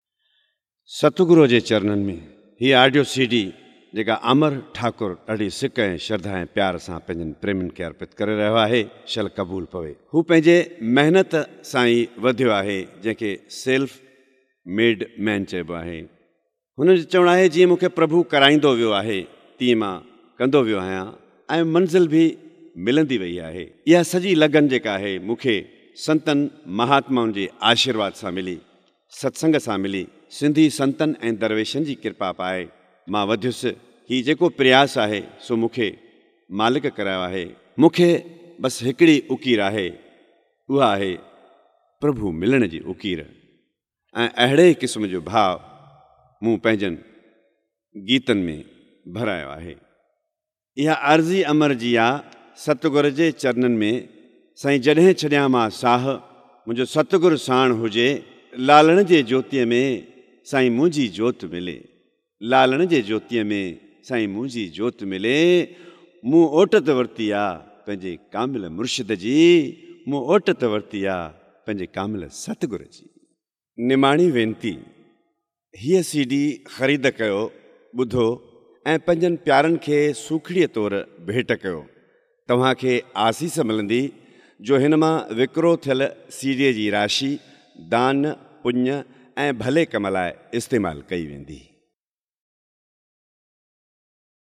Bhajans and Dhuni songs